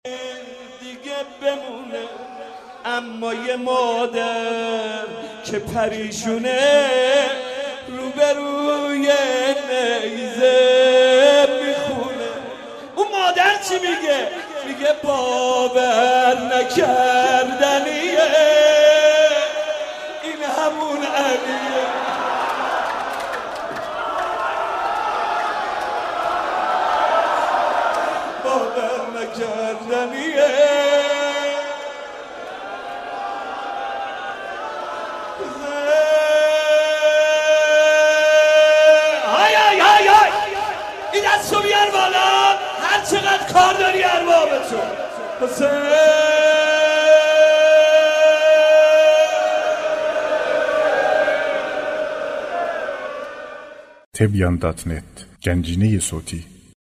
مداحی و نوحه
روضه خوانی، شهادت حضرت فاطمه زهرا(س